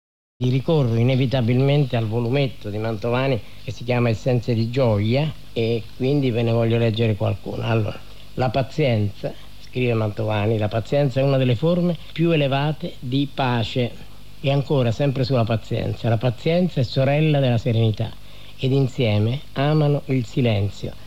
Aforismi sulla pazienza letti da Maurizio Costanzo
Il file audio è tratto da “L’uomo della notte”, programma su Radio 1 Rai nato negli anni ’70, ripreso poi nel 2007 da Maurizio Costanzo.